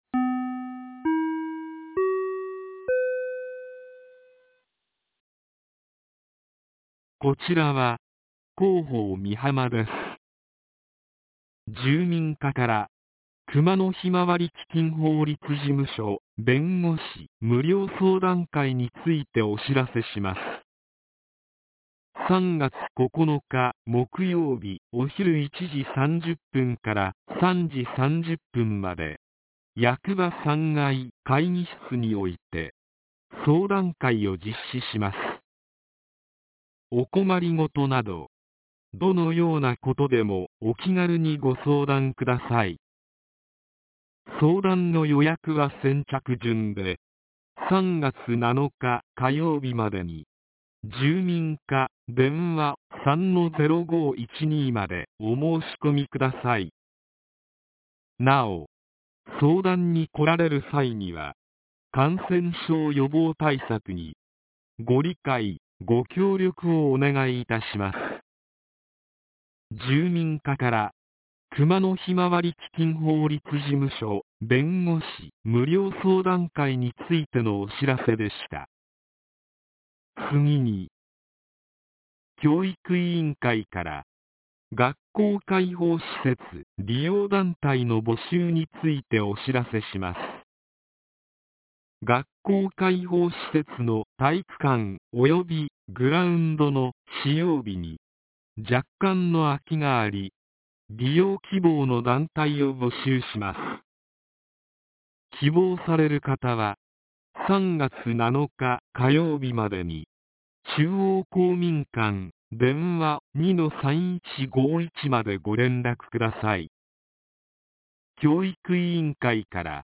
■防災行政無線情報■